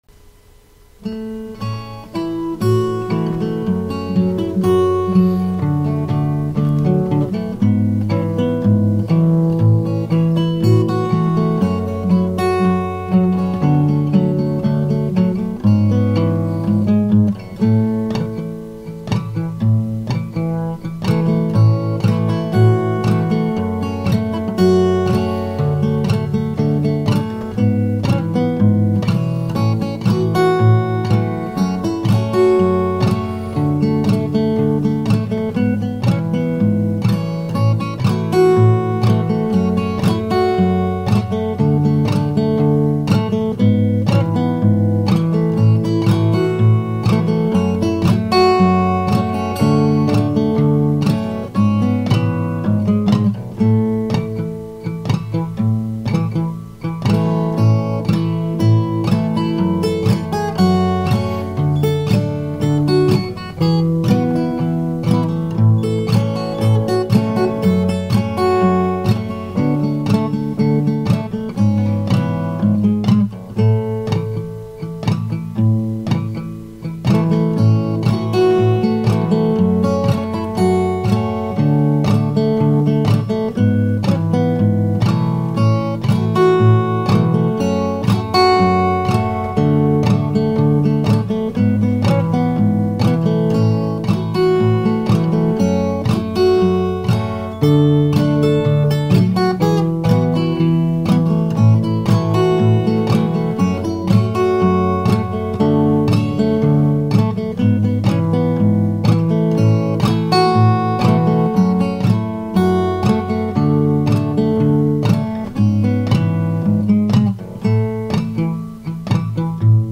Audio Clip from the Tutorial
Standard Tuning - 4/4 Time
Chord Progression: G, Em, C, D